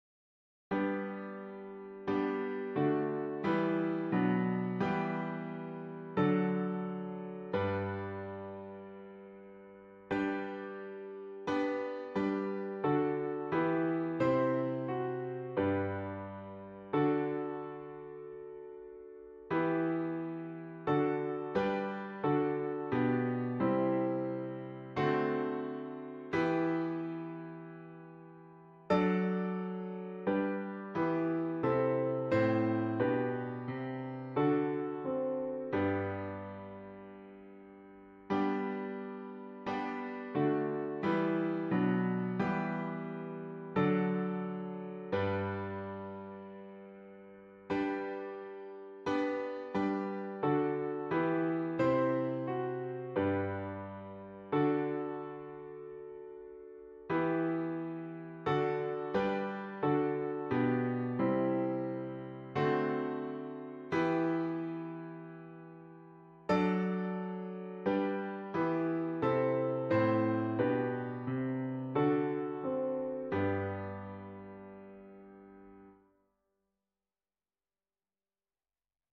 Old 100th for piano - MP3 & Midi files